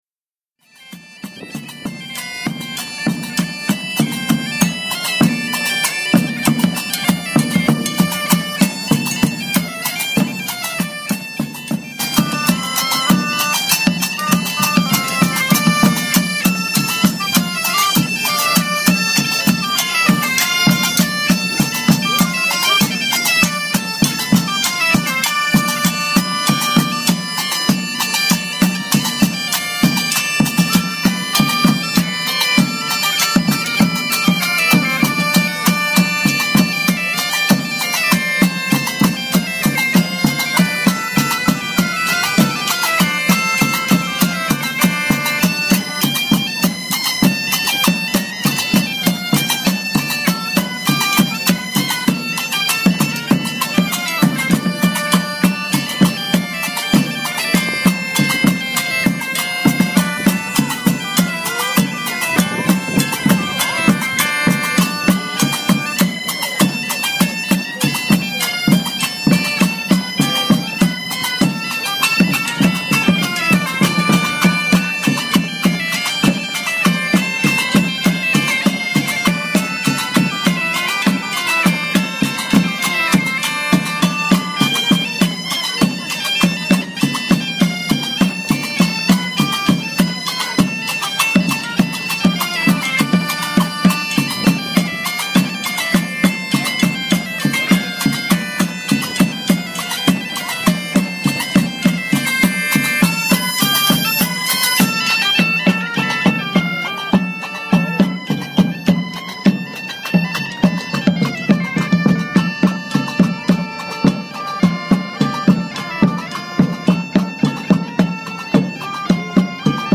El famoso mercado medieval de Ávila
No dejen de ver las fotografías con el sonido de músicos medievales,